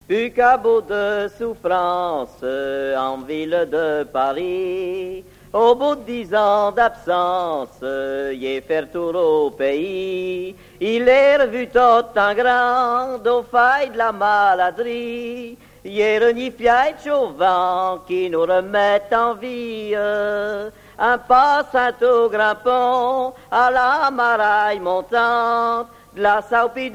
Le répertoire du groupe folklorique Torr'Niquett' pour la scène
Pièce musicale inédite